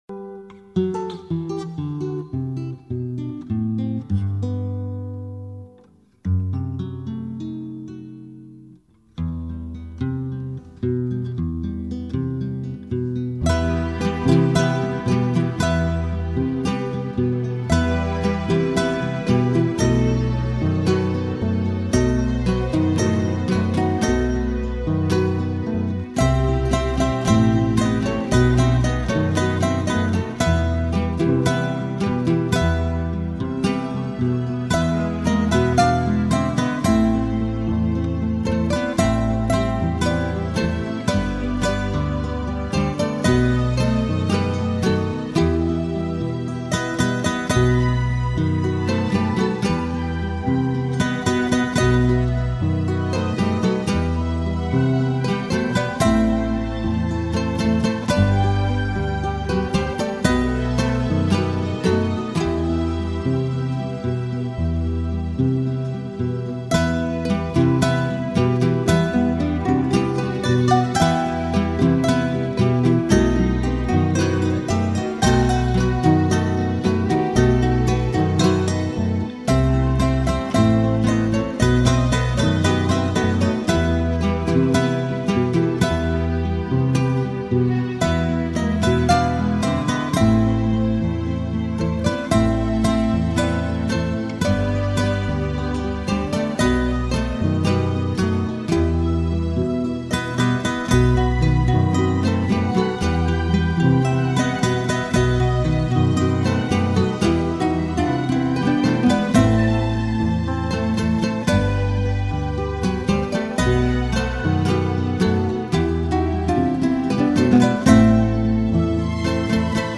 Guitarra